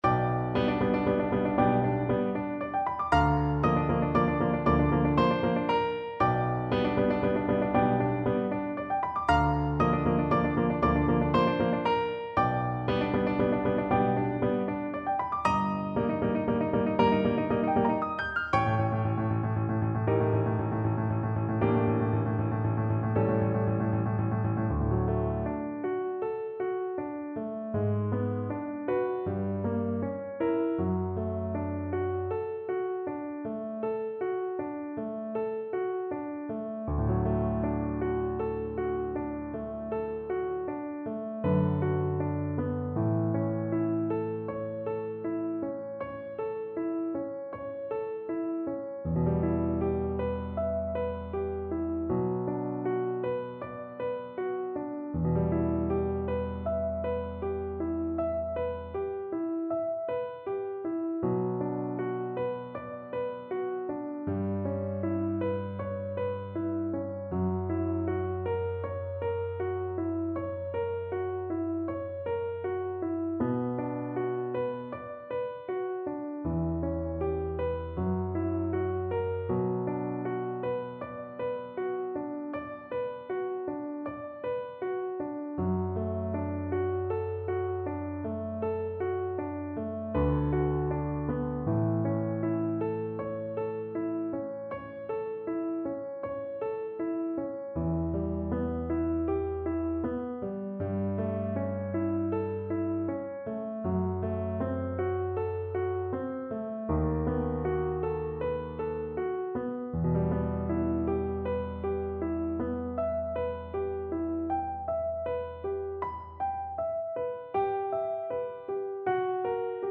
Cello version
6/8 (View more 6/8 Music)
Sostenuto =160 Sostenuto
Classical (View more Classical Cello Music)